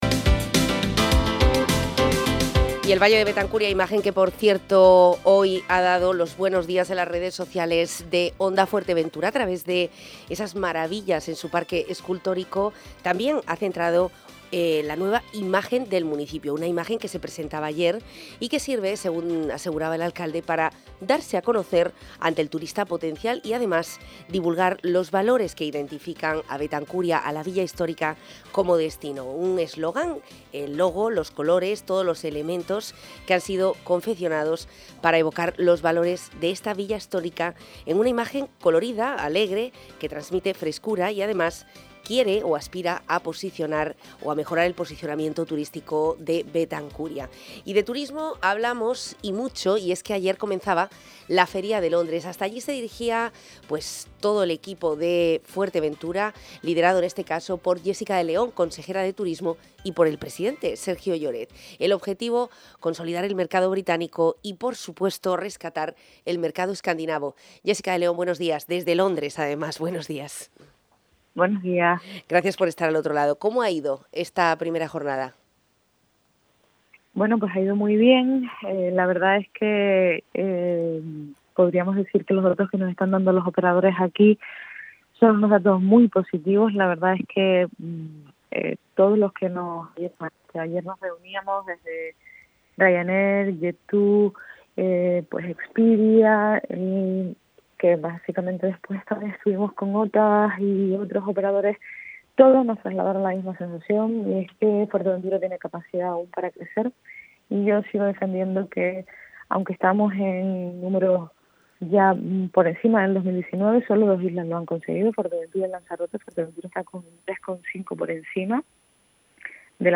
Jessica de León en El Magacín de Onda Fuerteventura desde Londres.
Buenas previsiones en el mercado turístico para Fuerteventura. Y de ello ha hablado en El Magacín de Onda Fuerteventura la consejera de Turismo Jessica de León.